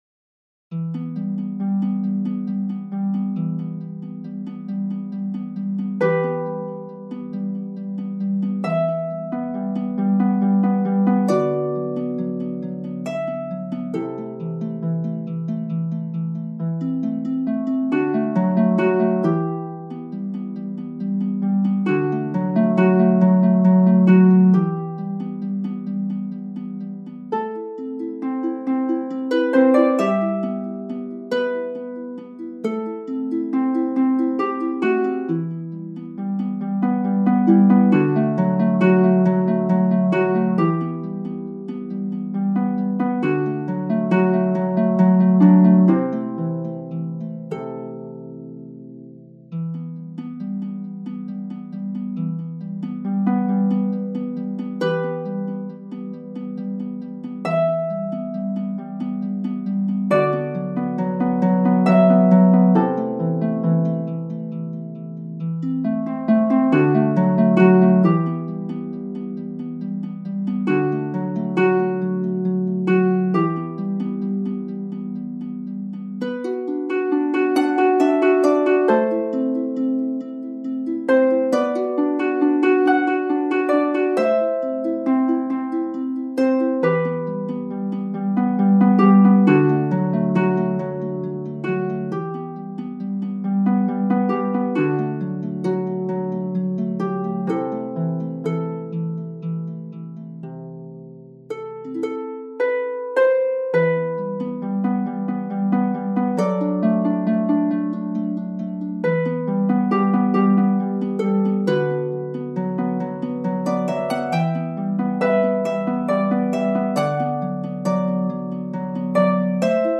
Harp